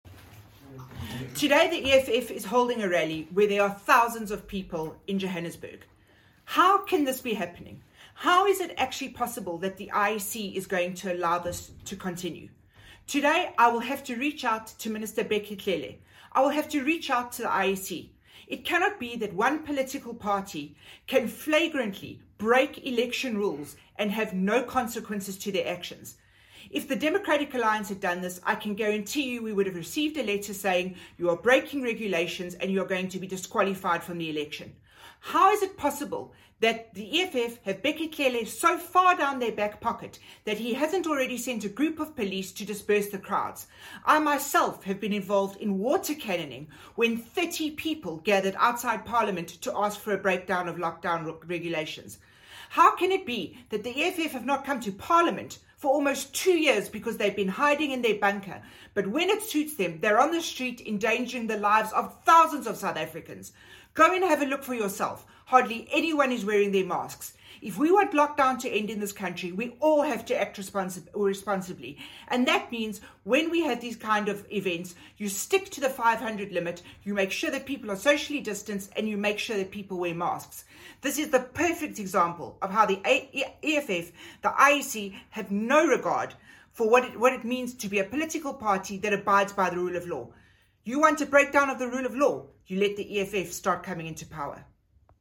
soundbite by Natasha Mazzone MP.